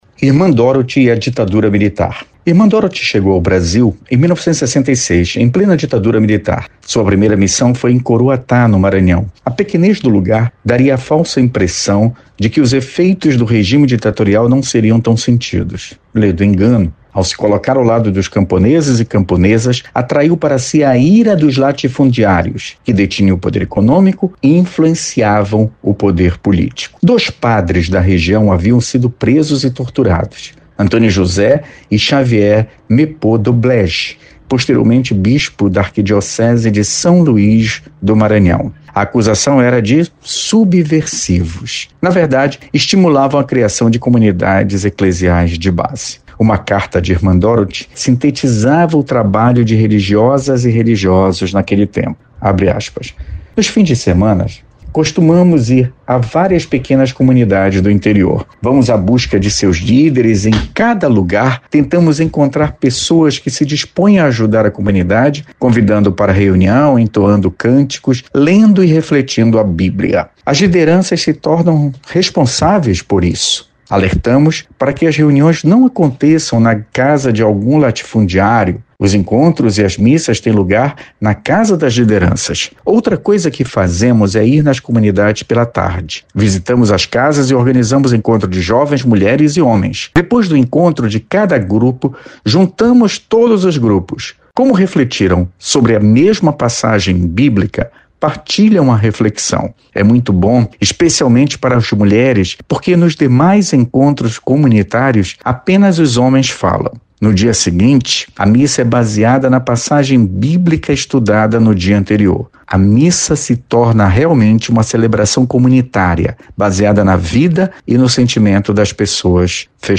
Confira na íntegra o editorial do Procurador Regional da República, Felício Pontes.